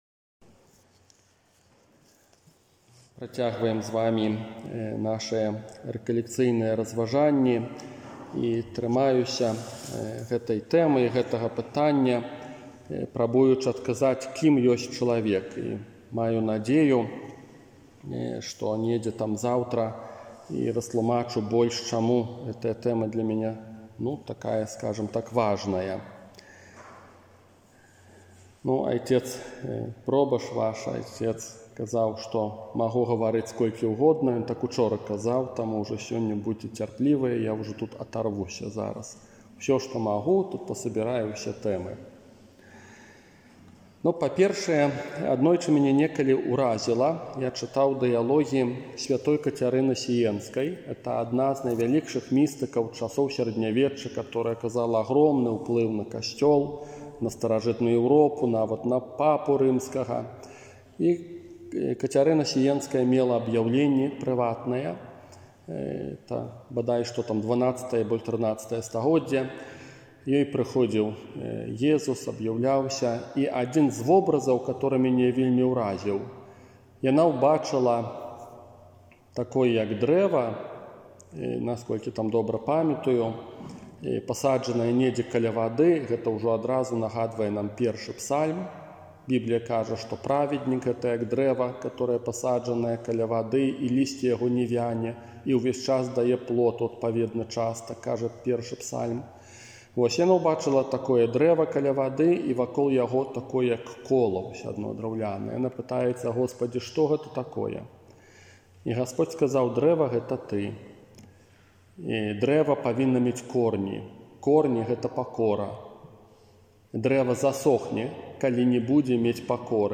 Для тых, хто не меў магчымасці ўдзельнічаць у Адвэнтавых рэкалекцыях, а таксама для ўсіх зацікаўленых, прапаную рэкалекцыі, якія адбыліся ў Вялікай Бераставіцы